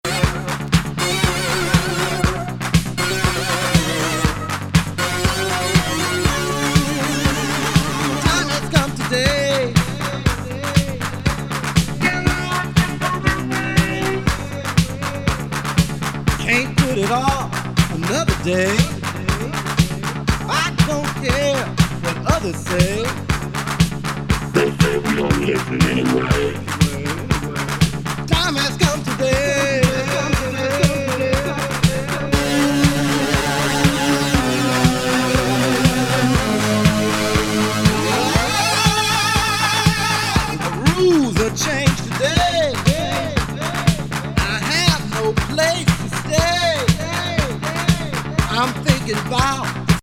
エグめ凶暴シンセ・ベースにダビーVo＆ボコーダー！